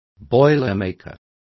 Complete with pronunciation of the translation of boilermaker.